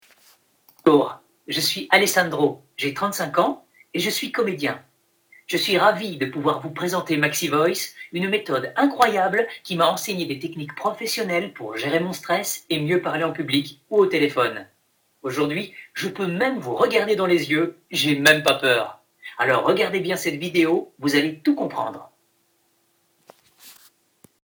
Voici la prise que je lui ai envoyée, ne faites pas trop attention à la qualité, je l’ai ré-enregistrée depuis les hauts parleurs de mon ordinateur.
J’aime beaucoup votre voix, une voix souriante, tonique, jeune, très agréable à l’écoute, vous avez un timbre de voix singulier.
Votre interprétation est fluide, rythmée, votre sourire est présent c’est bien.
Mais je pense que vous pouvez être plus naturel encore, la lecture est juste un peu saccadée (voir technique de la canne à pêche), il faut tout simplement lier un peu plus encore les mots les uns au autres.